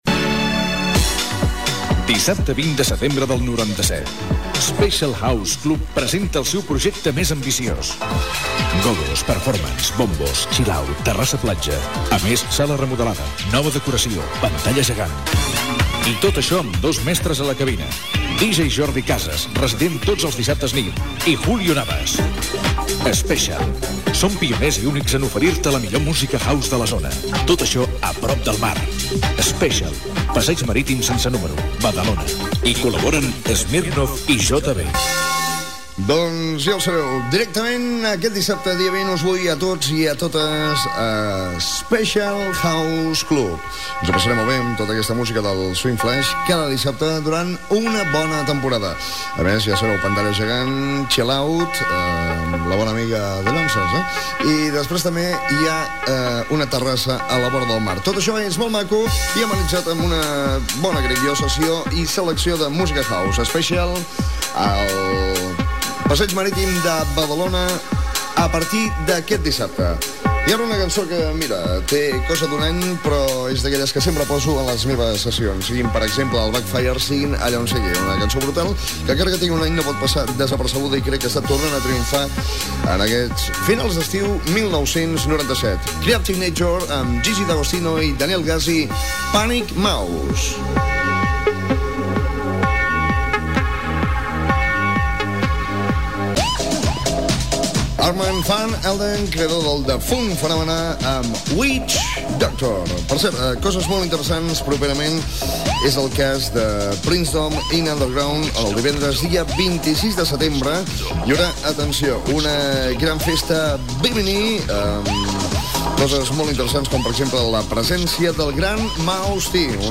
Publicitat, tema musical, anuncis de sessions de DJ, adreça de l'emissora, temes musicals, data, tema musical
Musical